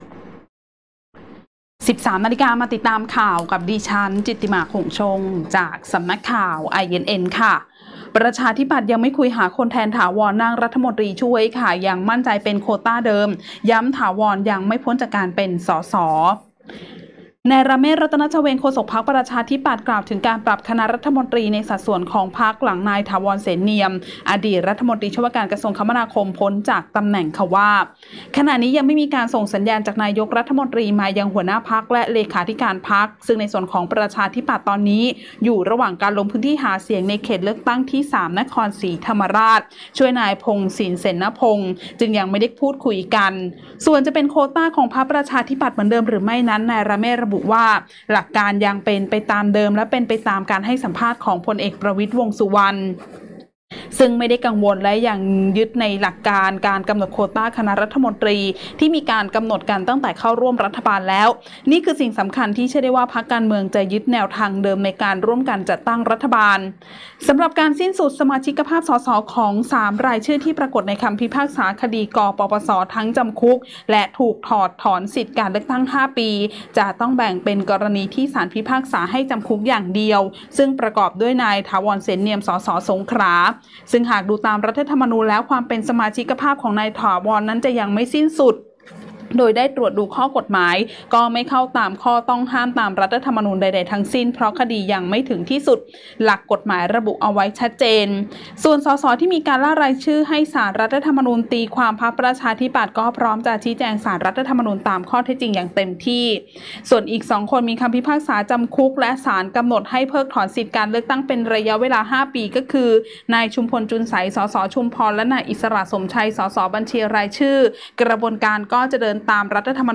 ข่าวต้นชั่วโมง 13.00 น.